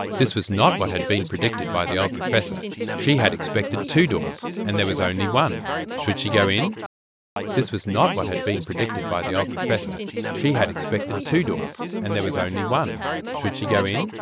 In these scenarios, the face covering would muffle the talker, making it harder to hear, because the background sounds themselves are not muffled by the mask.
We have therefore simulated the effects of listening to speech produced from under each of the above masks, with a background of either a babble of four talkers or a single talker. These each consist of seven seconds of the speech without the effect of the face covering, followed by the same seven seconds of speech but including the effect of the face covering.
Single talker against competing four-talker babble:
babble_FFP3foldflat+5dBSNR.wav
babble_ffp3foldflatplus5dbsnr.wav